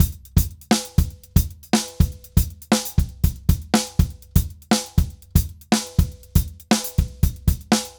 Drums_Baion 120_2.wav